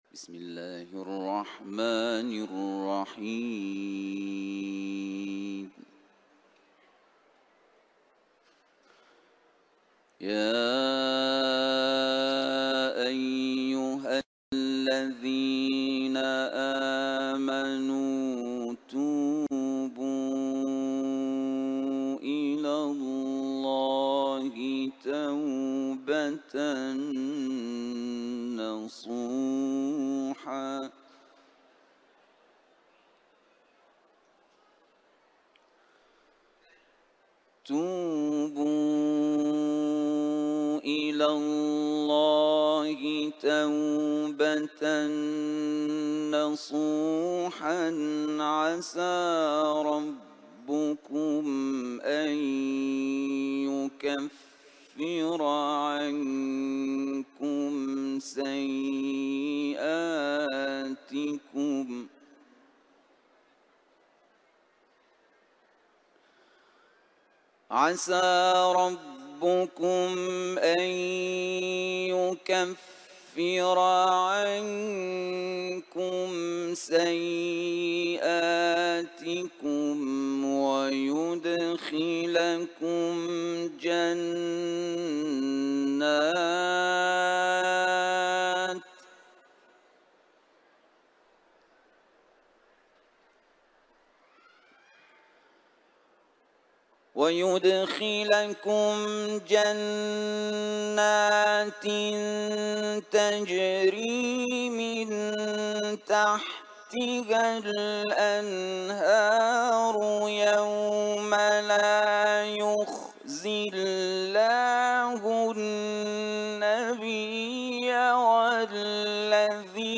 تلاوت‌